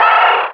Cri de Zigzaton dans Pokémon Rubis et Saphir.